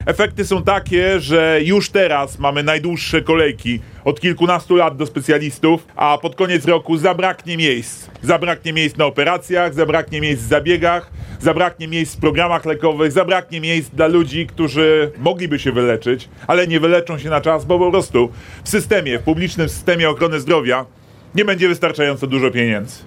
W Białej Podlaskiej polityk przypomniał też, że już w grudniu ubiegłego roku rząd przewidział 20 milionów mniej na fundusz zdrowia, a teraz przegłosowano zamianę, która wyjmuje z publicznego systemu kolejne 6 miliardów złotych.